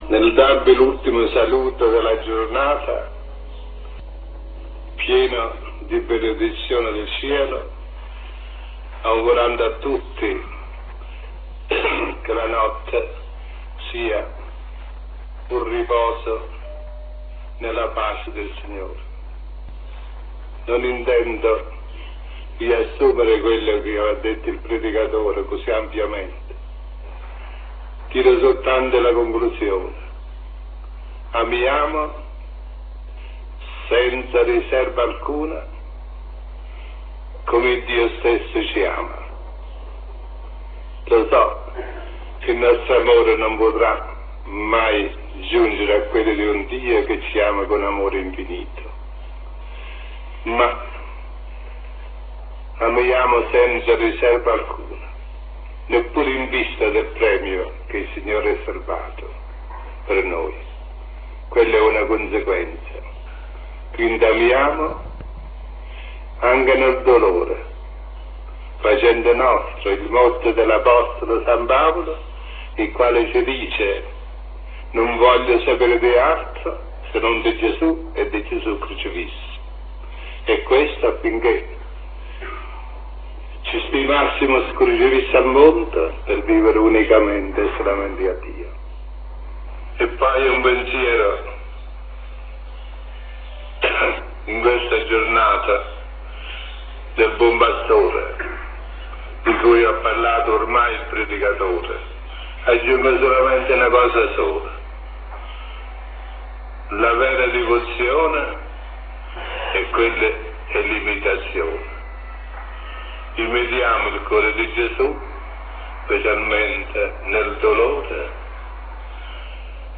Qui sotto trovate quattro files della voce di Padre Pio.
Preghiera della notte (279 Kb)